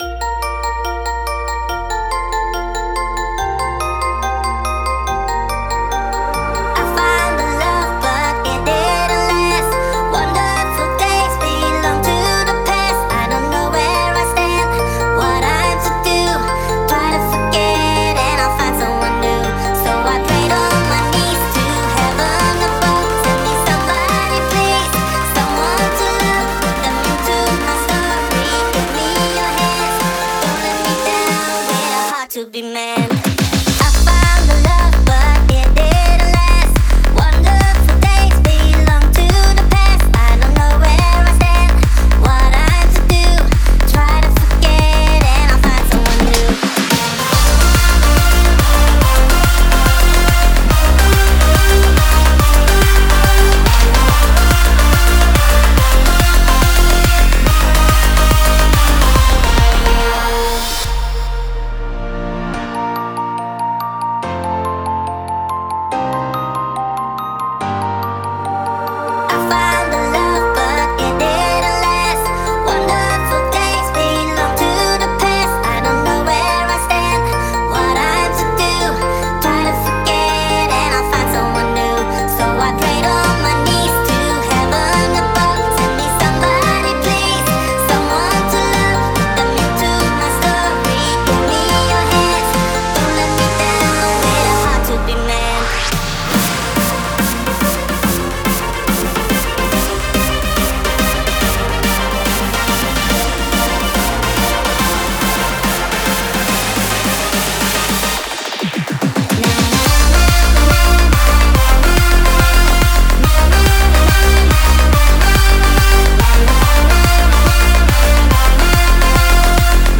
это энергичная трек в жанре хардкор-хаус